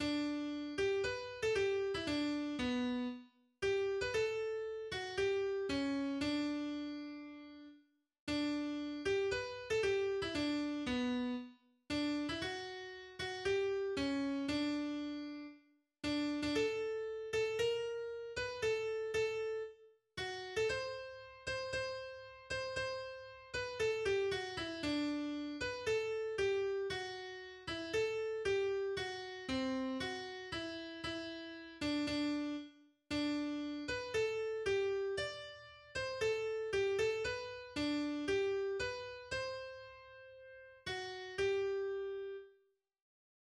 Studentenlied des späten 19. Jahrhunderts